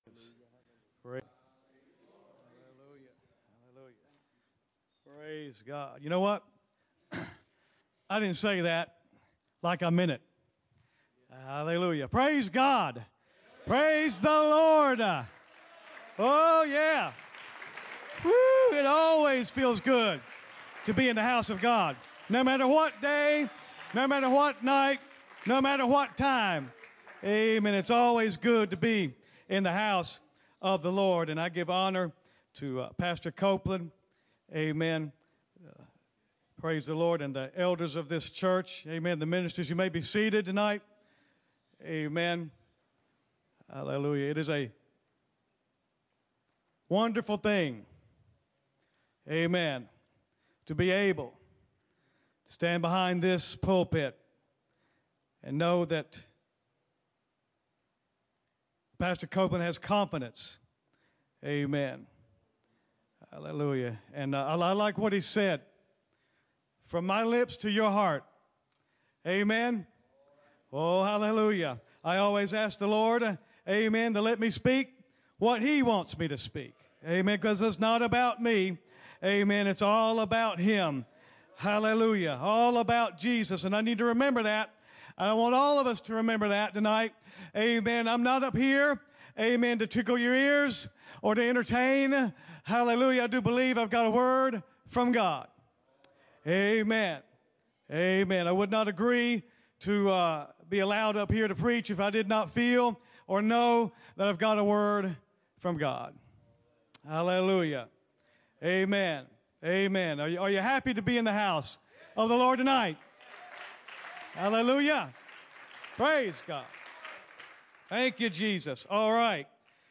First Pentecostal Church Preaching 2018